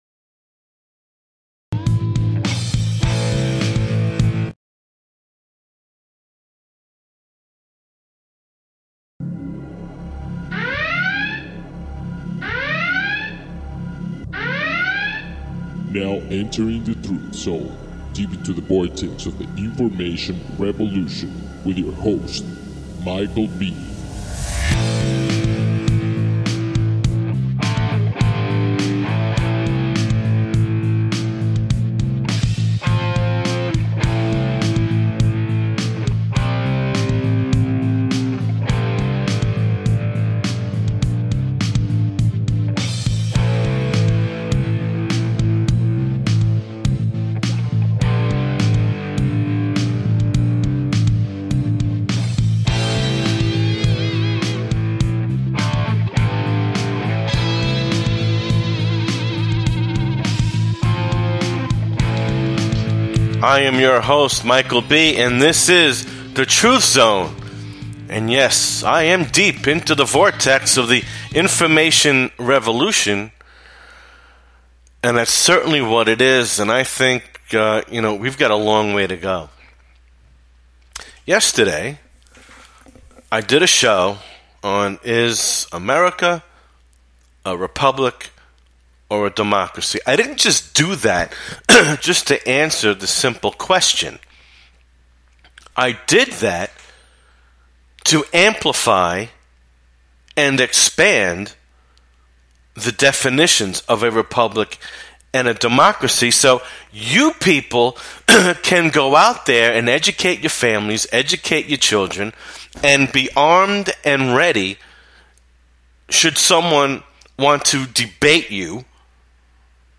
The Truth Zone is in your face radio and not for the weak of heart.